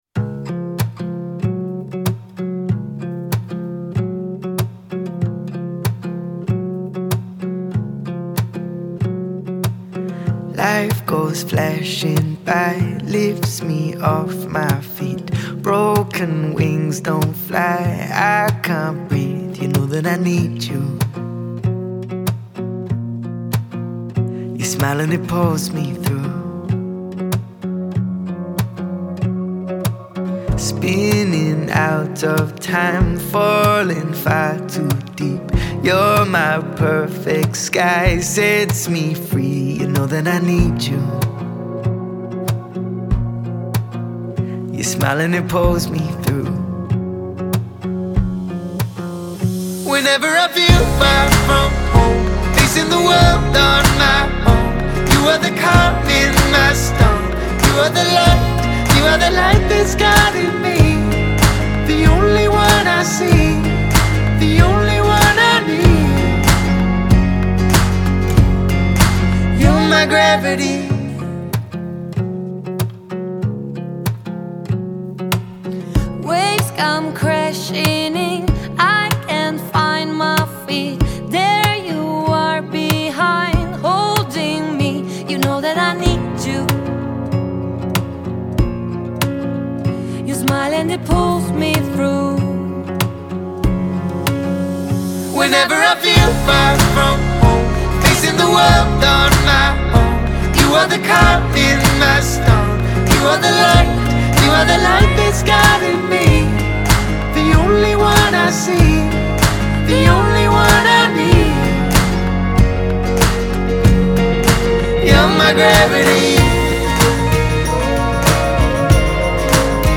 это эмоциональная поп-баллада